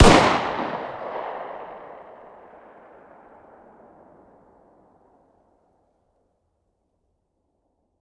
fire-dist-40sw-pistol-ext-06.ogg